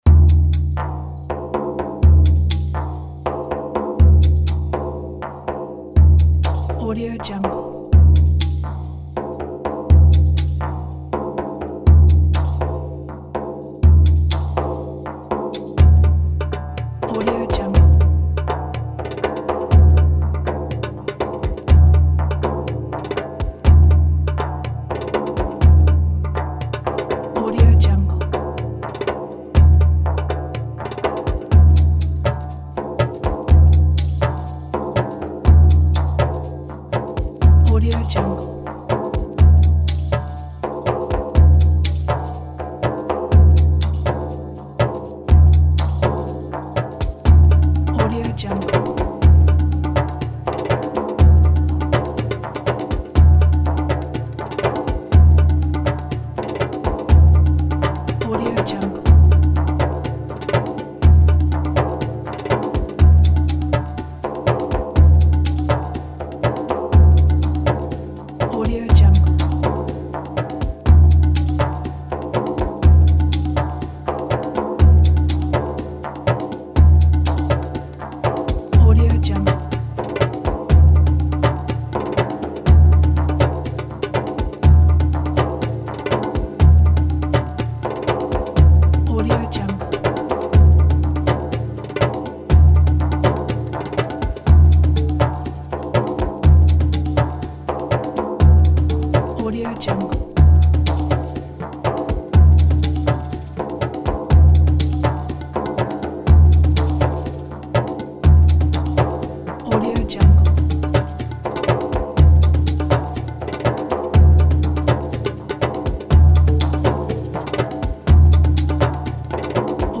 پرکاشن
percussion-preview-1.mp3